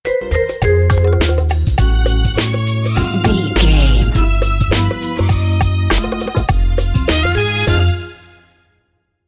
Short music, corporate logo or transition between images,
Epic / Action
Fast paced
In-crescendo
Uplifting
Ionian/Major
bright
cheerful/happy
powerful
groovy
funky
synthesiser